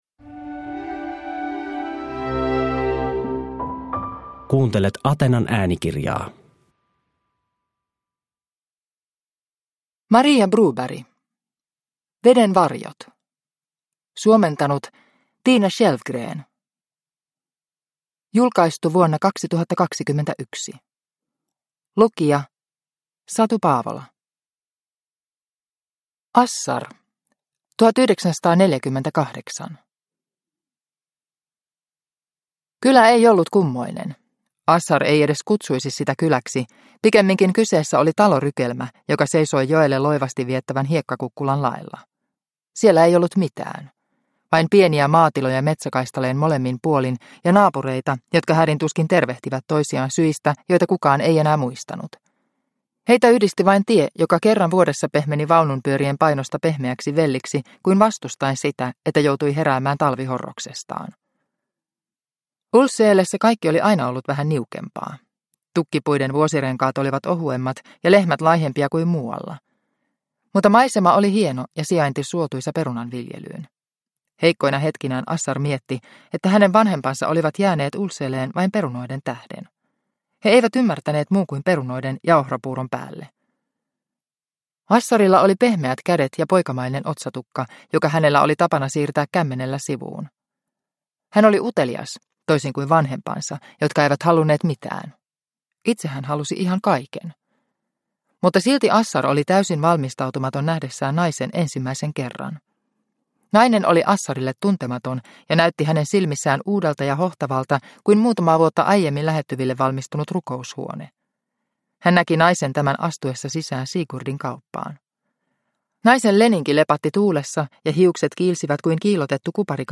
Veden varjot – Ljudbok – Laddas ner